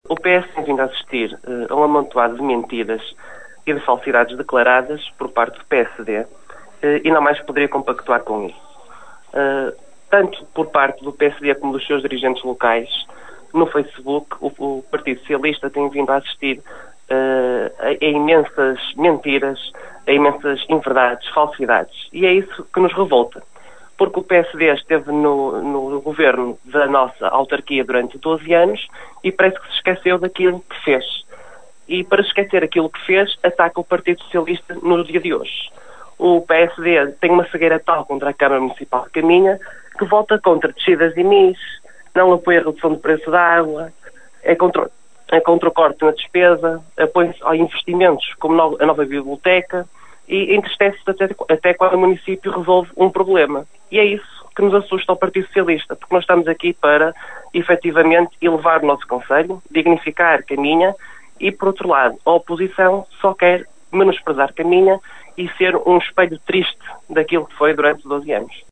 Em declarações à Rádio Caminha